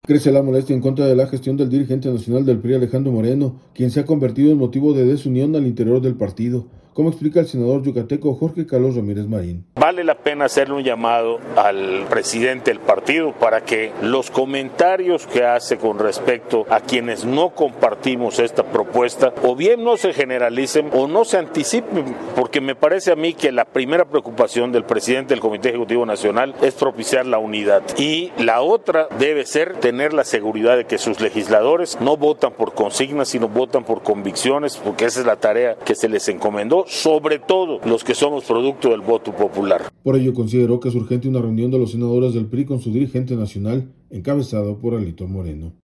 Crece la molestia en contra de la gestión del dirigente nacional del PRI, Alejandro Moreno, quien se ha convertido en motivo de desunión al interior del partido, como explica el senador yucateco, Jorge Carlos Ramírez Marín.